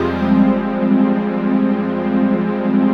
HOUSPAD11.wav